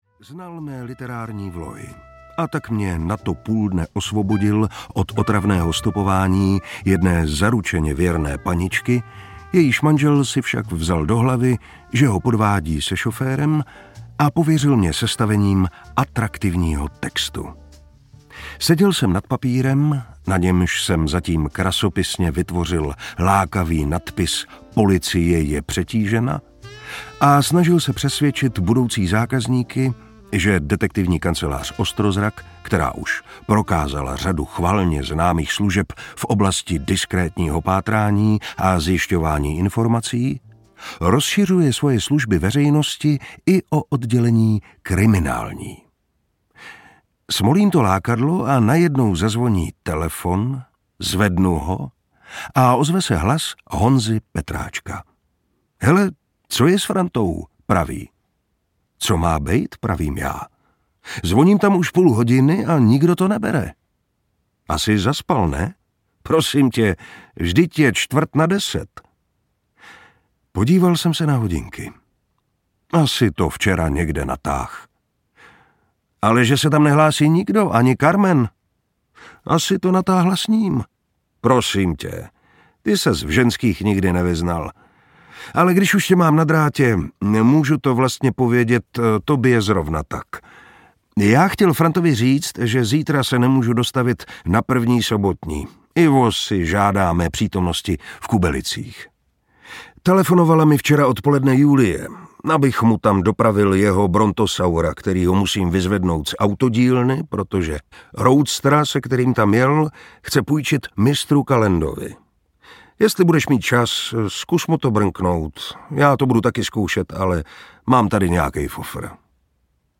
Vražda pro štěstí audiokniha
Ukázka z knihy
• InterpretMartin Preiss